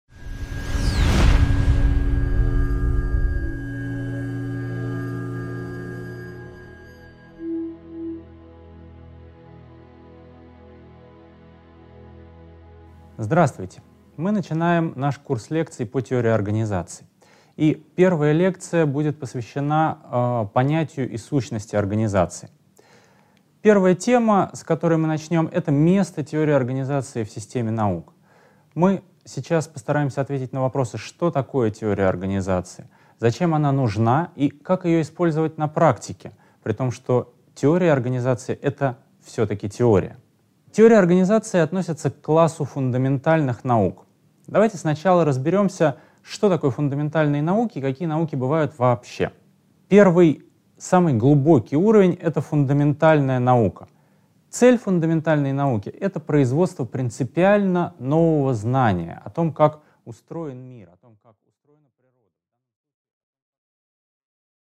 Аудиокнига 1.1. Место теории организации в системе наук | Библиотека аудиокниг